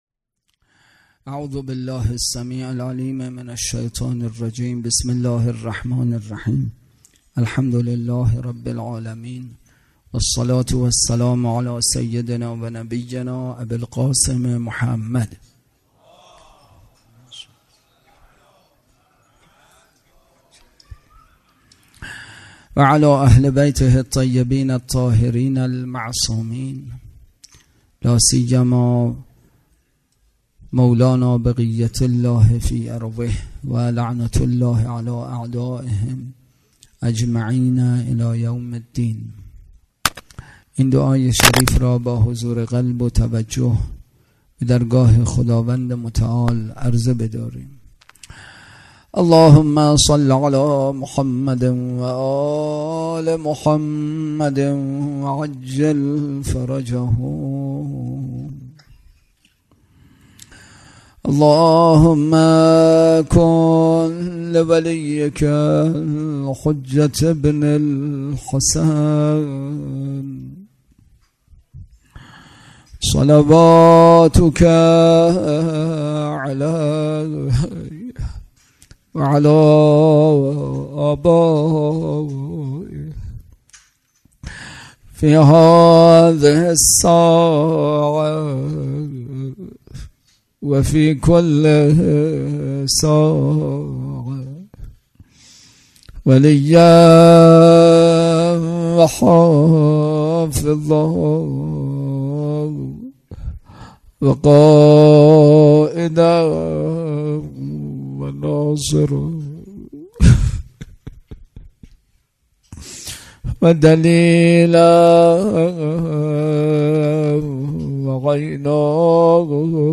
سخنرانی
برگزارکننده: مسجد اعظم قلهک